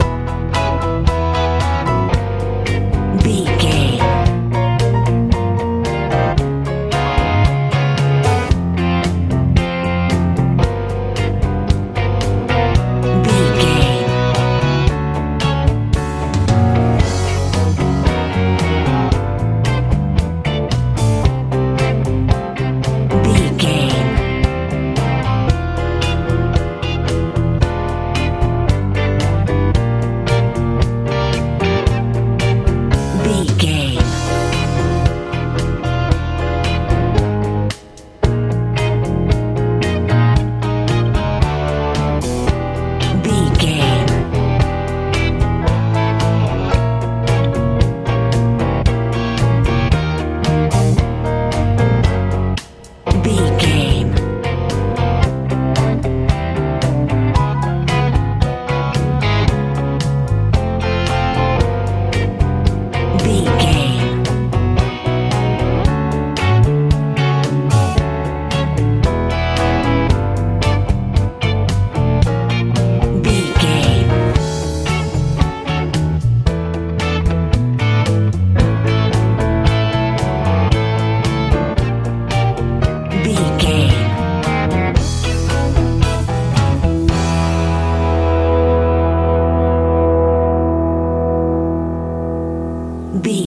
driving country rock feel
Ionian/Major
electric guitar
bass guitar
piano
drums
mellow
smooth
soft